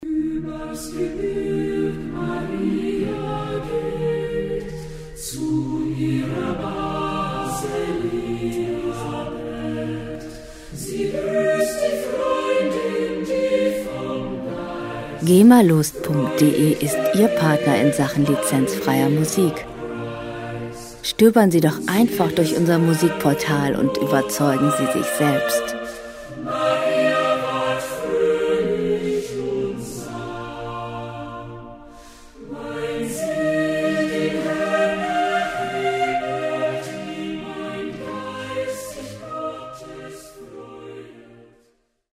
Musikstil: Choral
Tempo: 98 bpm
Tonart: B-Dur
Charakter: spirituell, sakral